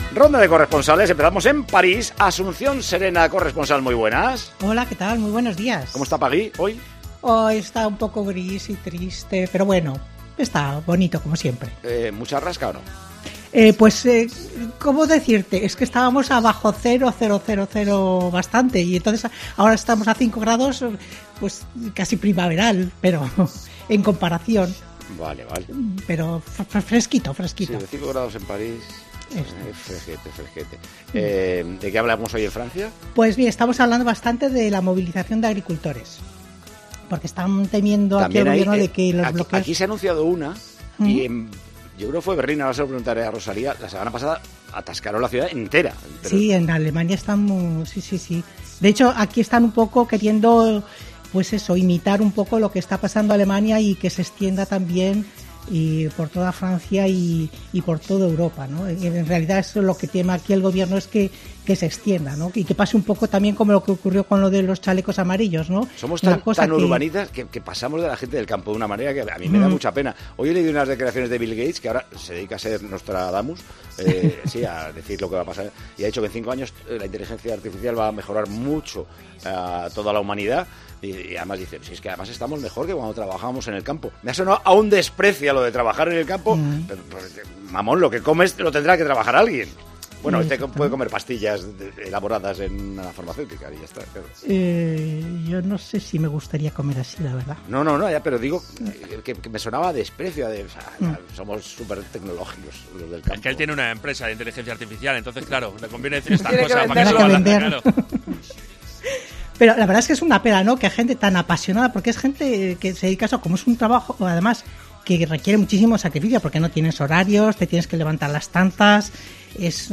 Paco González consideró un tanto despectivas estas afirmaciones: "Somos tan urbanitas que nos olvidamos de la gente del campo", afirmó el presentador de Tiempo de Juego.
Con Paco González, Manolo Lama y Juanma Castaño